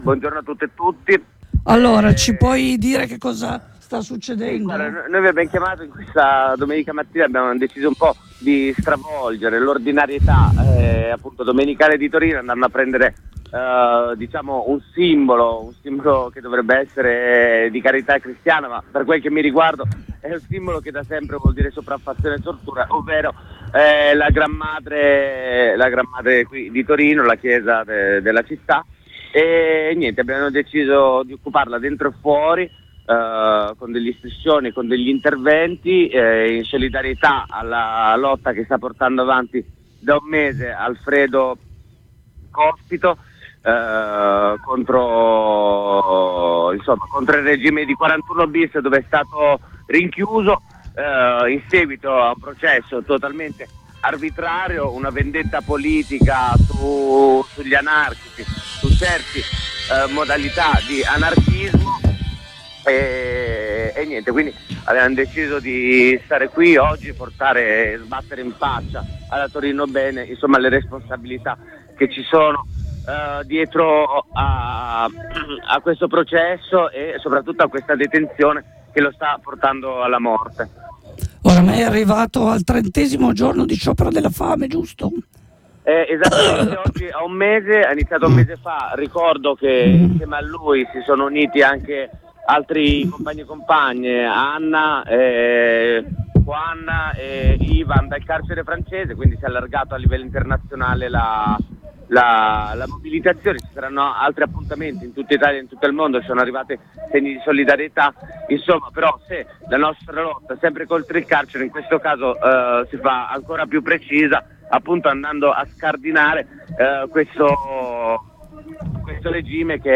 Rilanciamo una prima diretta dalle frequenze di Radio Blackout: Aggiornamento...